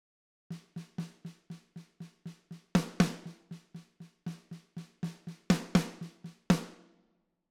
Вложения snare.mp3 snare.mp3 295,4 KB · Просмотры: 6.354